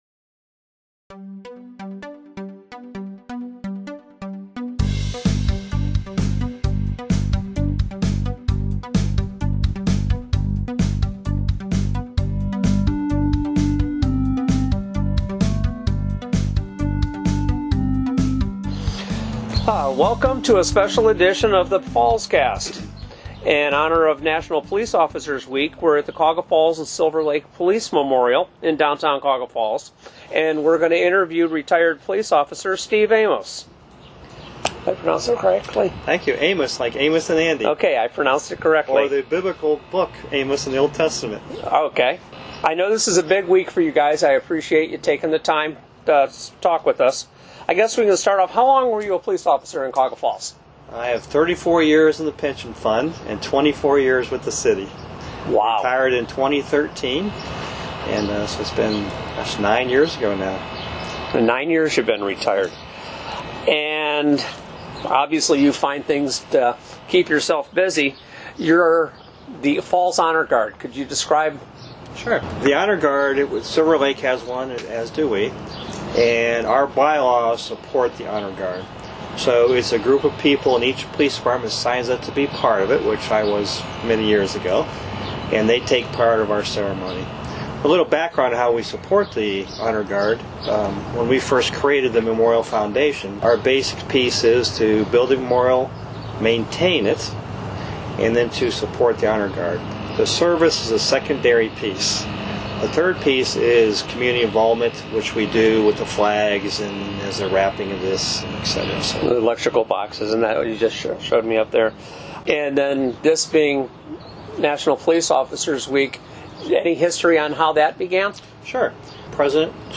Fallscast Bonus Interview